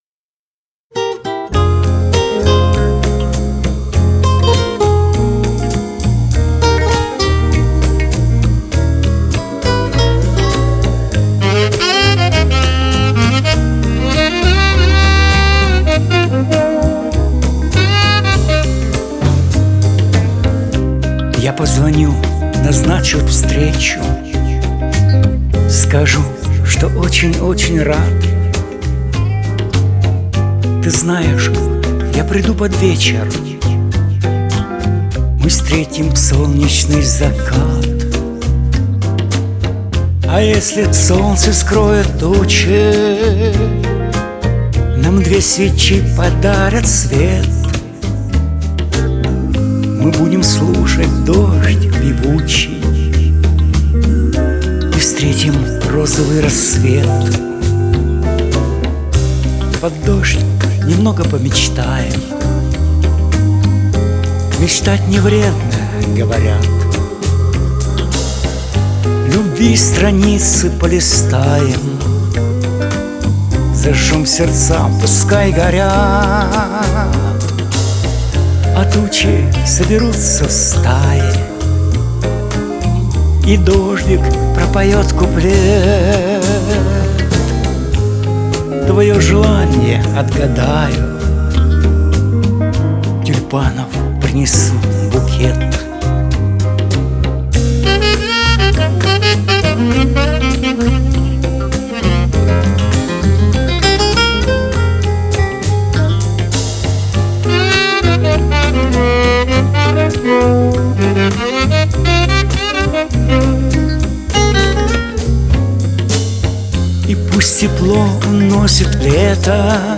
Адрес аудиозаписи песенного варианта в исполнении автора: